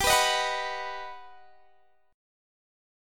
G+M7 chord